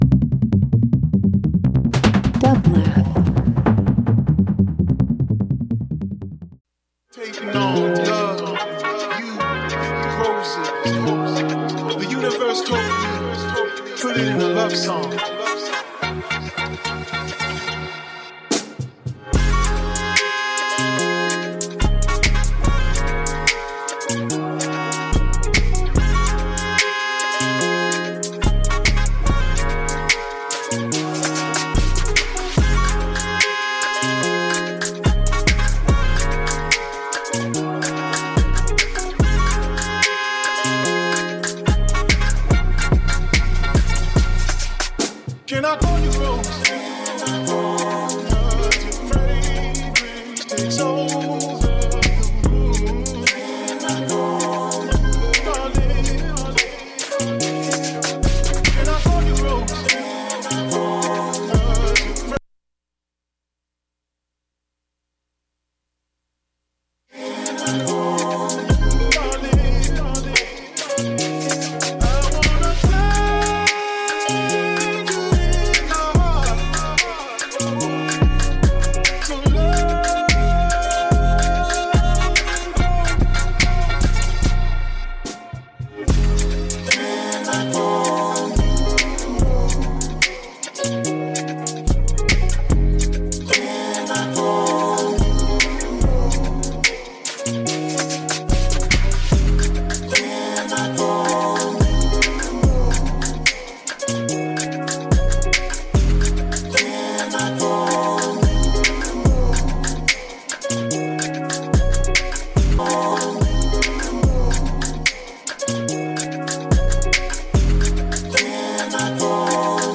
Hip Hop Pop R&B Rap